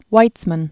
(wītsmən, vītsmän), Chaim Azriel 1874-1952.